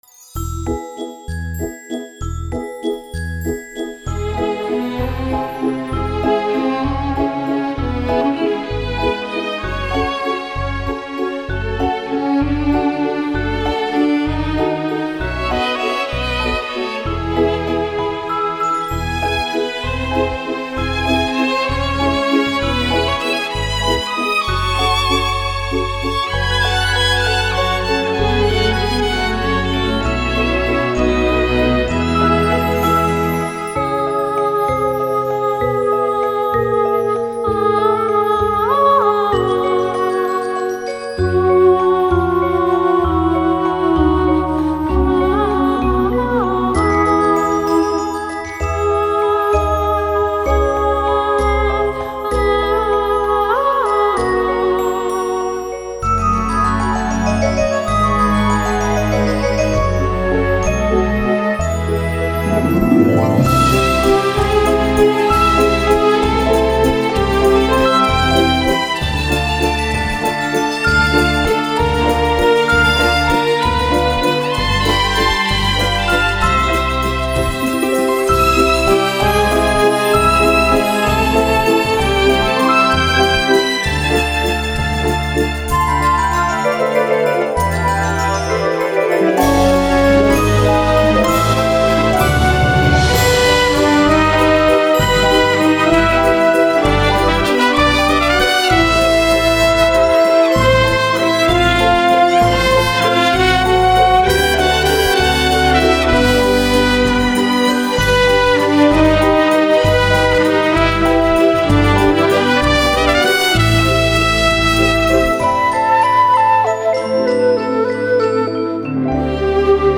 Нашёл студийную запись этой мелодии, но без вступления, немного в другой арранжировке и с женским голосом.